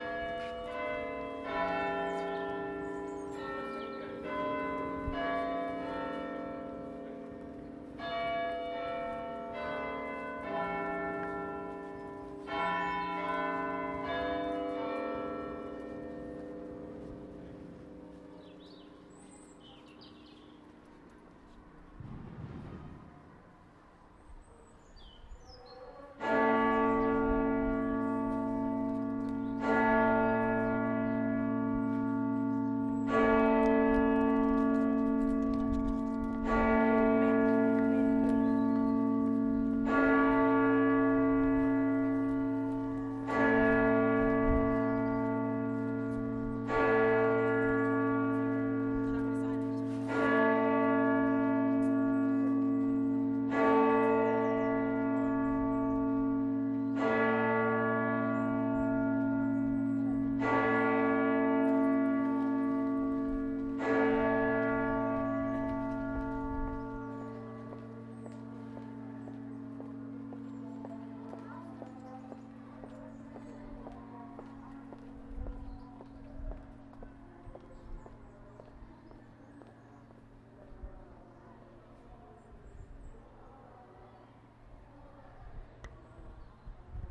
Lincoln chimes